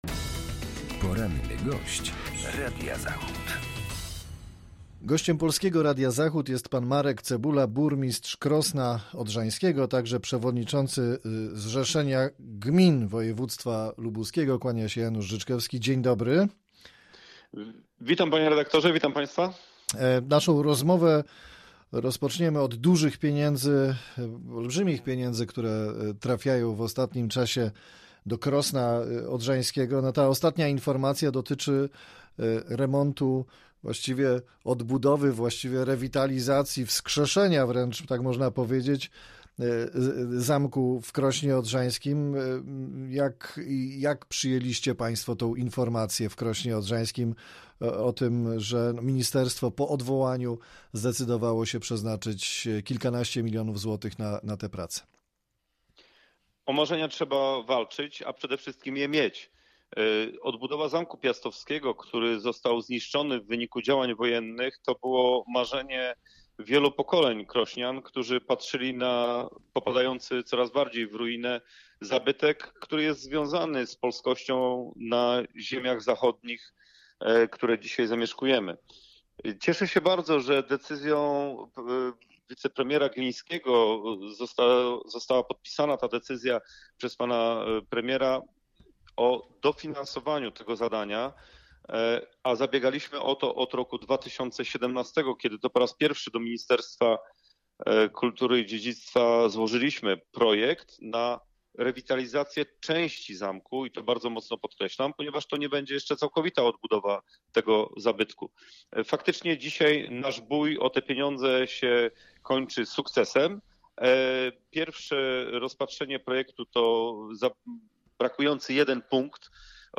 Z burmistrzem Krosna Odrzańskiego, przewodniczącym Zrzeszenia Gmin Województwa Lubuskiego rozmawia